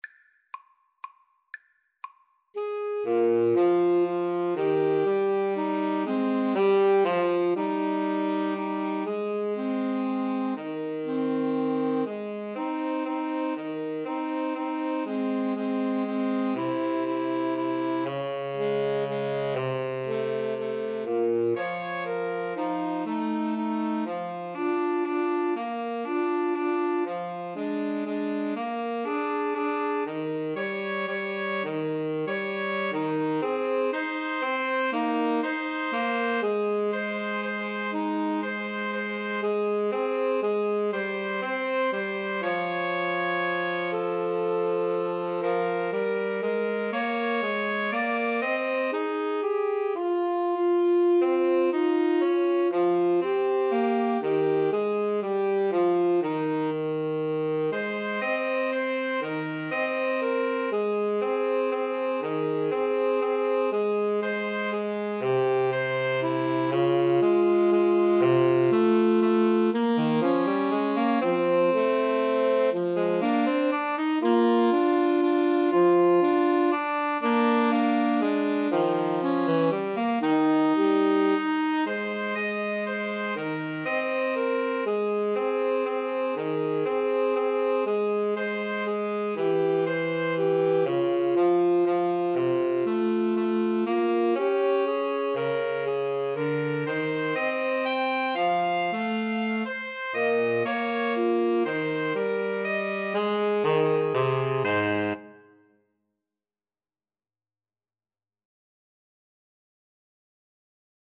= 120 Tempo di Valse = c. 120